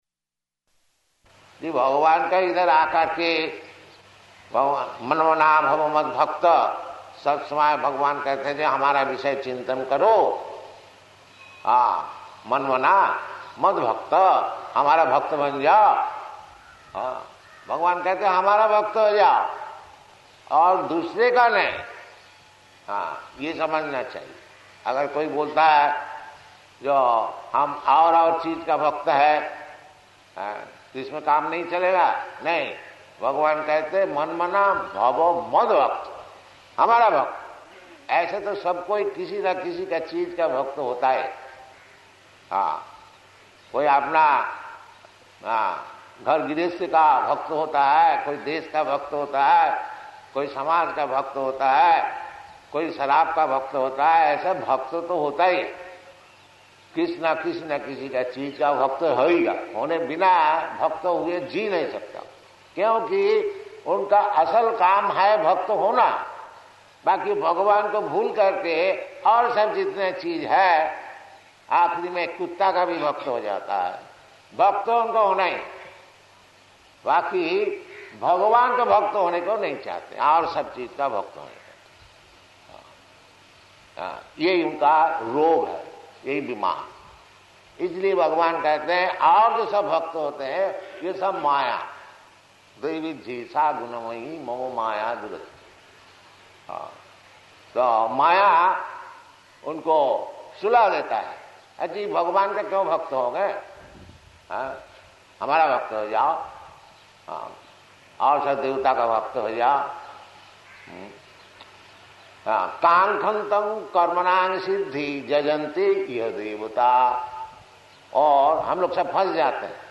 Lecture in Hindi
Type: Lectures and Addresses
Location: Hyderabad